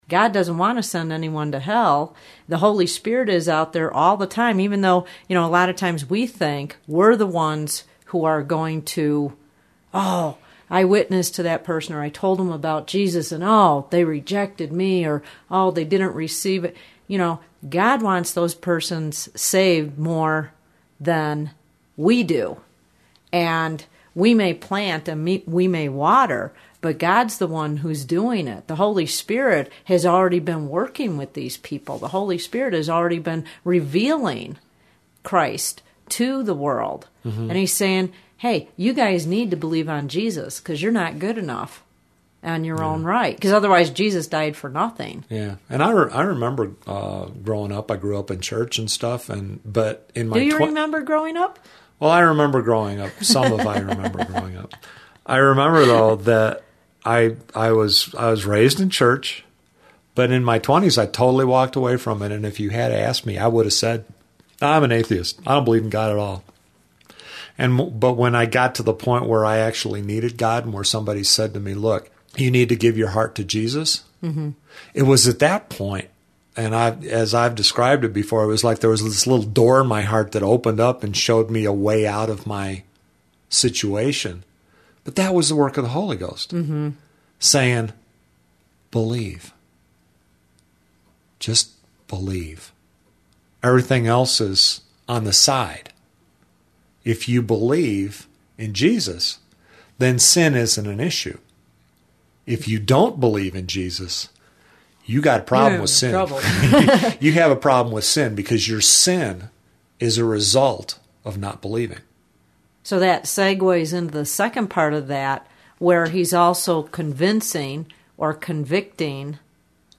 Join us in this intimate, conversational setting as we examine the role of the Holy Ghost -- from A to Z -- as He leads, inspires, reveals, sets us free, quickens, sanctifies, and teaches!